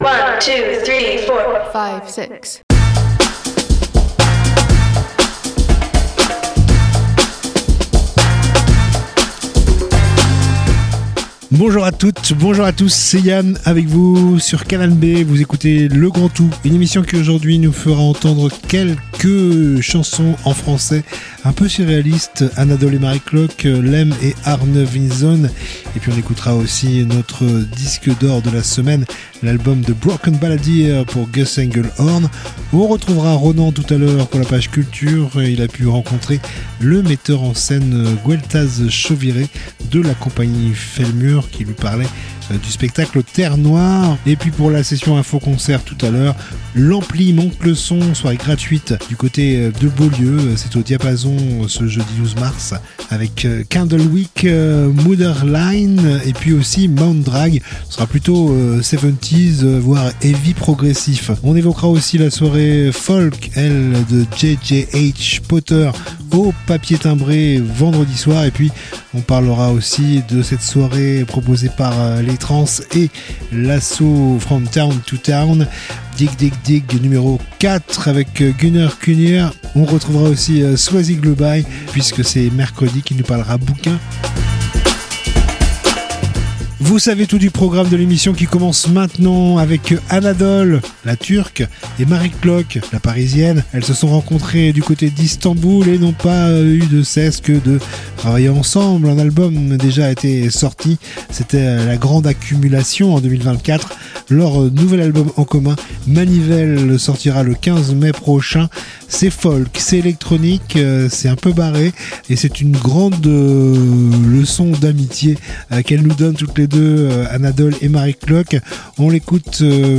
itv culture